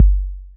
pcp_kick17.wav